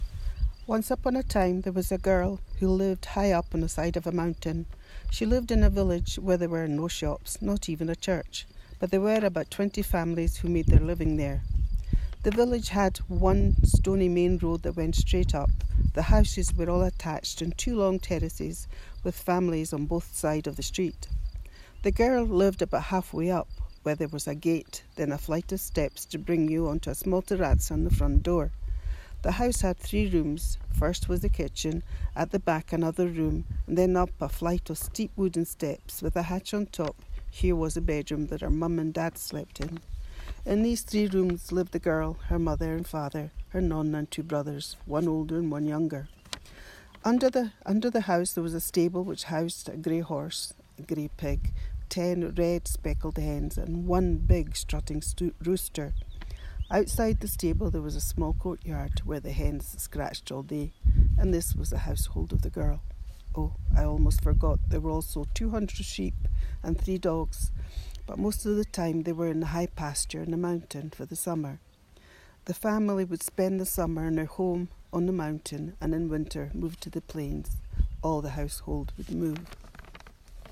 on location outside her old house in Fontitune.